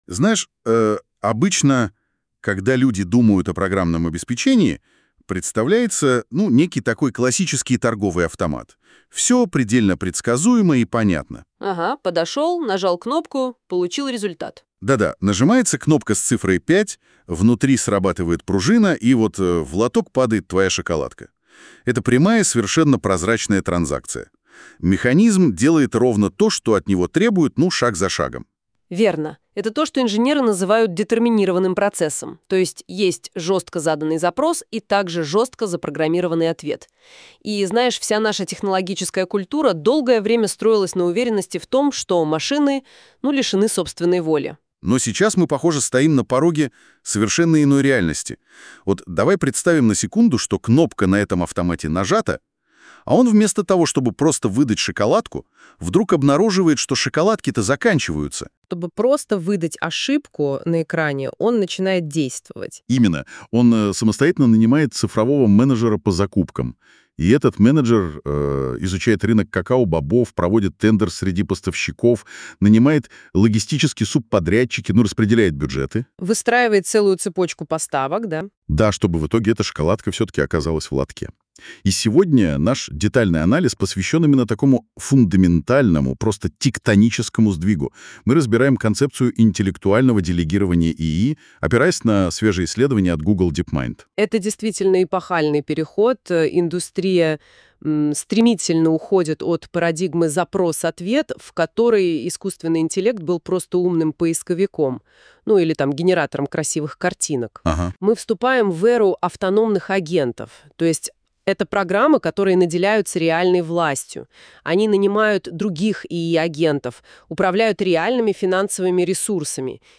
Можно послушать также подкаст, сделанный по переводу с помощью NotebookLM.